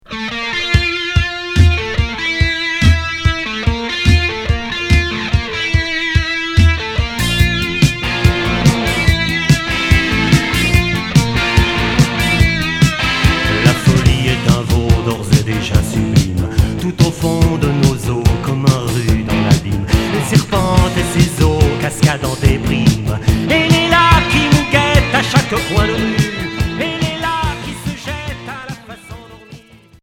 Pop rock Sixième 45t retour à l'accueil